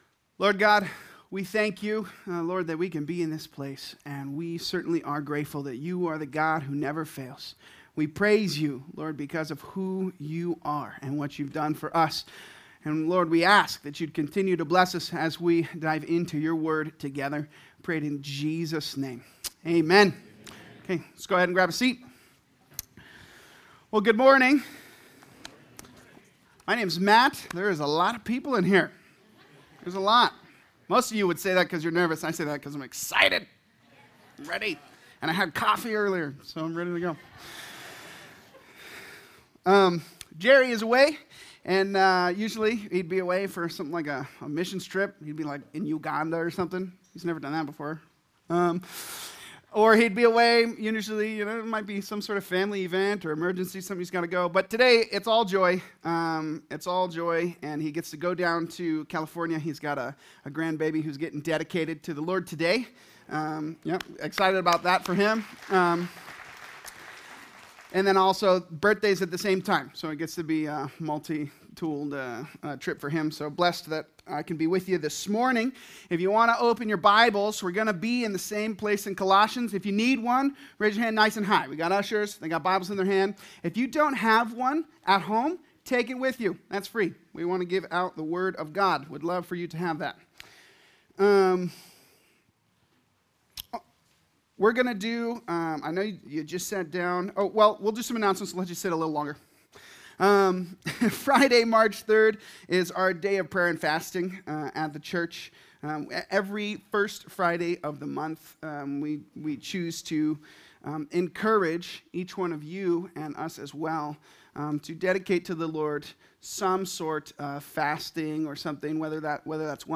Calvary Chapel Saint George - Sermon Archive
Related Services: Sunday Mornings